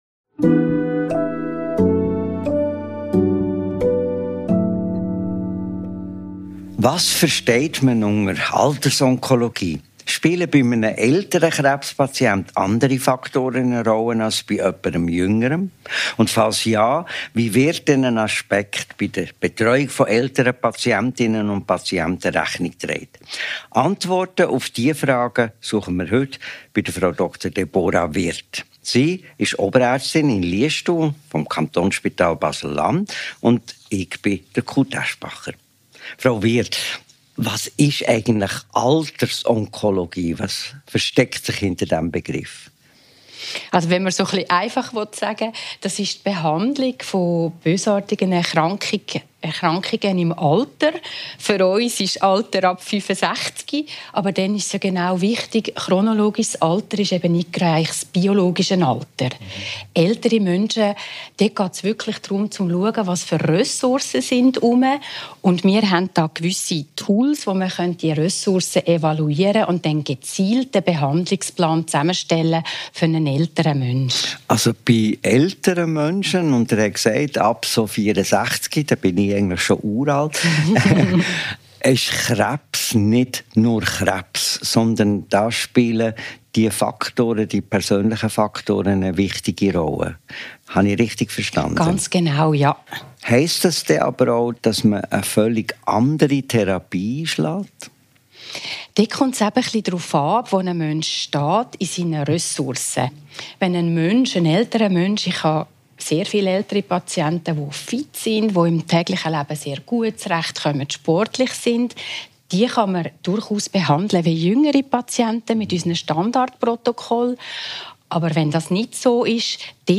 Ein Gespräch über die Gratwanderung zwischen Lebensqualität und Überlebenszeit, über den Stellenwert der Familie in der Entscheidungsfindung, über Palliation, Patientenverfügungen und darüber, warum 75% der befragten Patienten angeben, dass ihnen die Lebensqualität wichtiger ist als die Länge des Lebens.